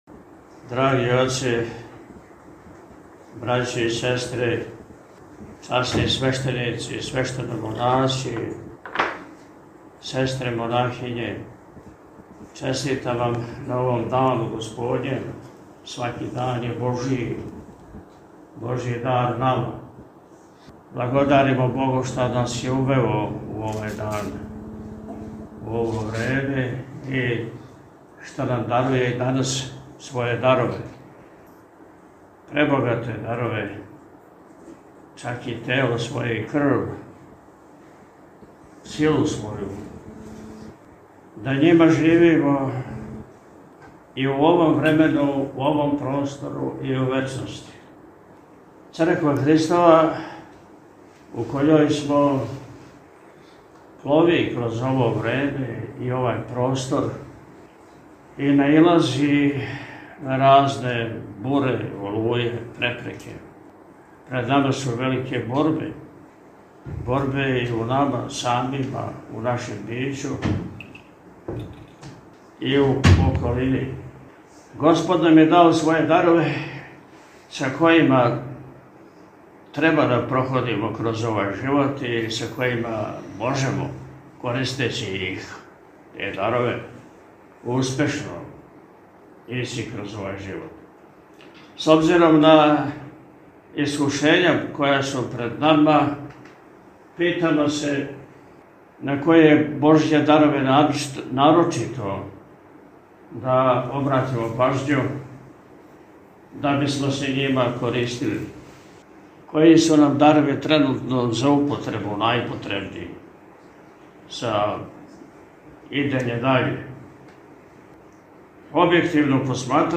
По отпусту Високопреосвећени је сабраном верном народу, у пастирској беседи, између осталог рекао: – Црква Христова, у којој смо, плови кроз ово време и овај простор и наилази на разне буре и олује, препреке. Пред нама су велике борбе.